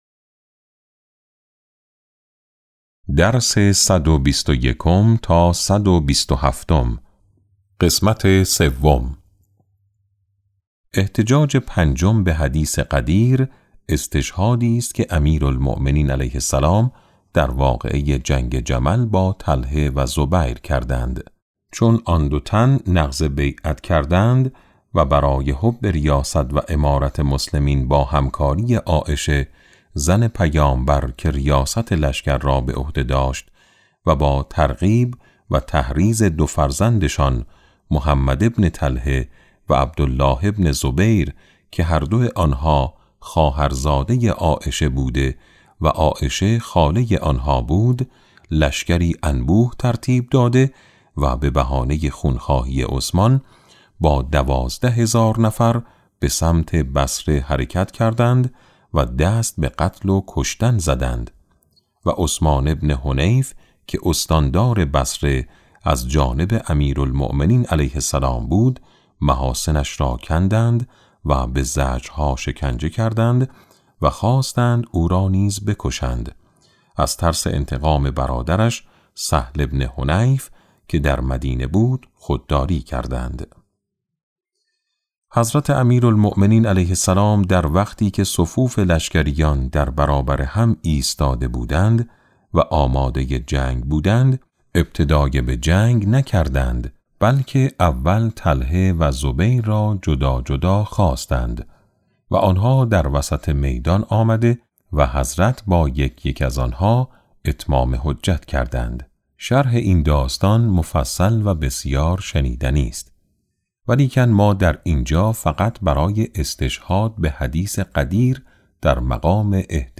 کتاب صوتی امام شناسی ج9 - جلسه3